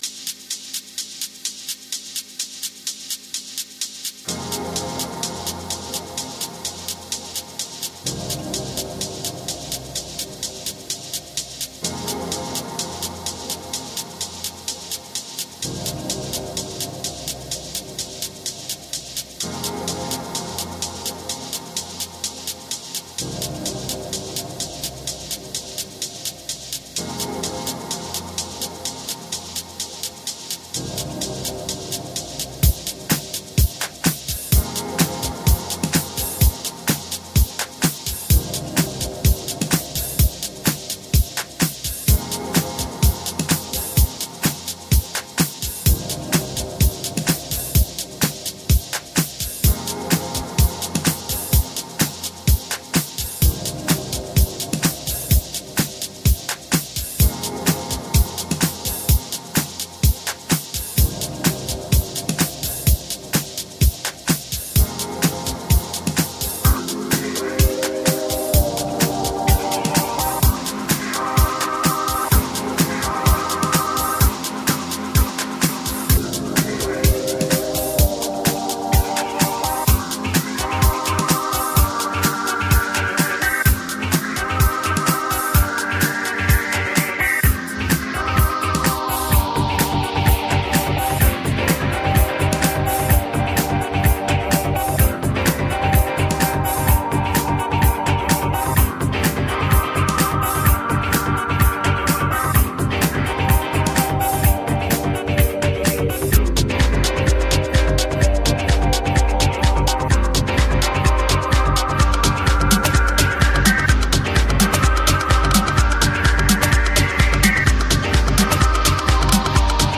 techier take on the original